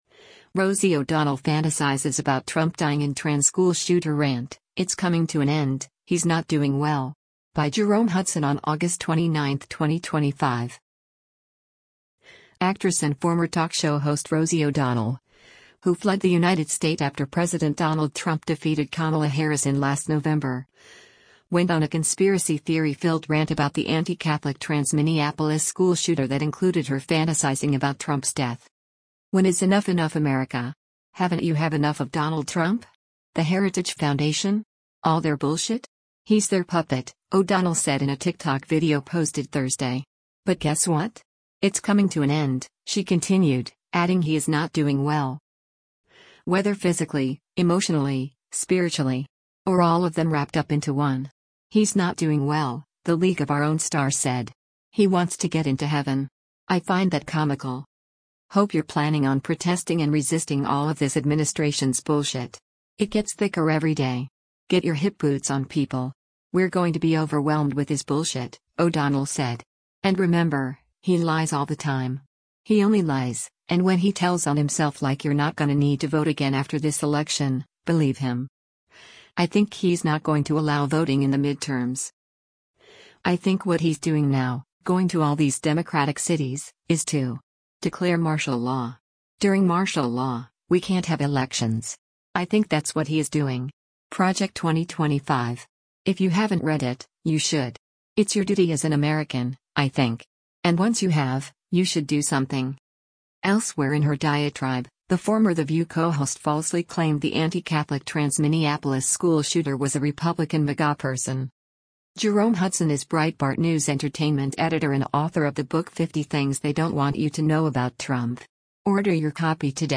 “But guess what? It’s coming to an end,” she continued, adding “he’s not doing well.”